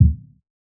KICK LOW END II.wav